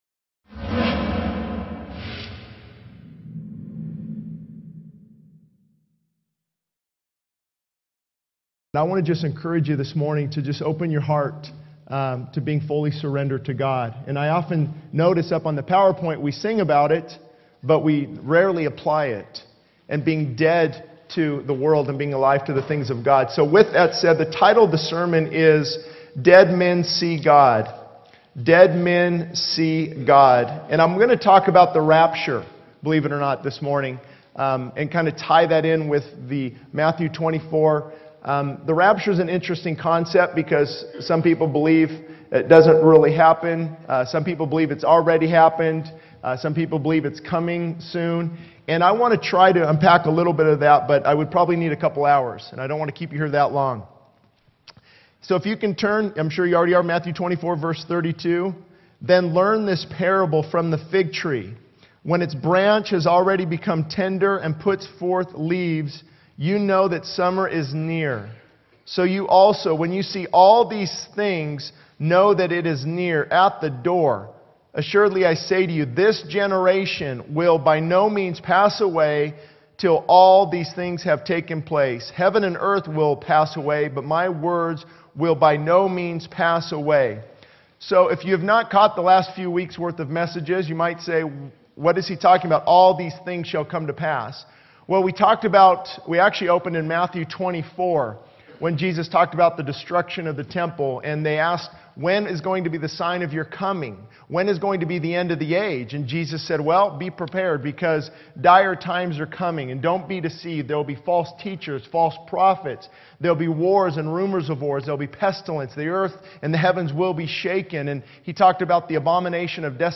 This sermon emphasizes the importance of being fully surrendered to God, focusing on the need to be dead to the world and alive to the things of God. The title 'Dead Men See God' is explored in the context of discussing the rapture and Matthew 24. The sermon delves into the urgency of being prepared for Christ's return, highlighting the signs of the times and the need to be watchful.